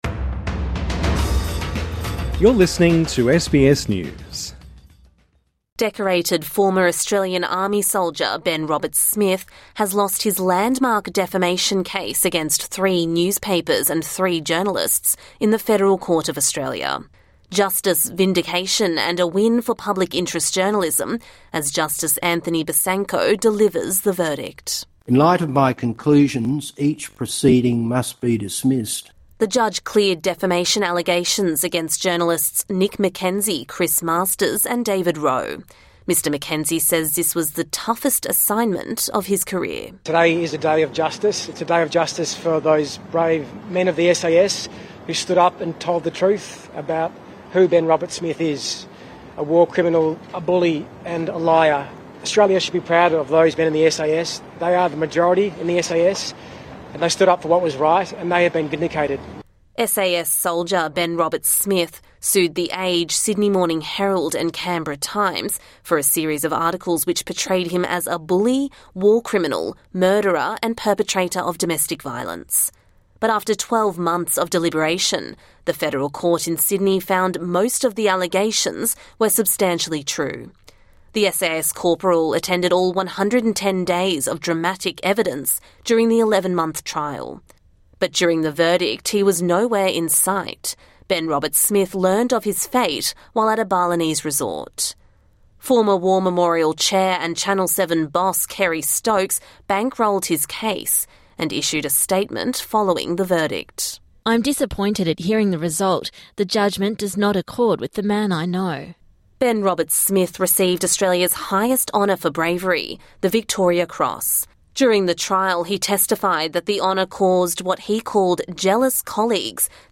Hear the story behind the headlines. In under ten minutes each episode, we’ll help you make sense of the news stories that matter to you from Australia and the world, with reports and interviews from the SBS News team.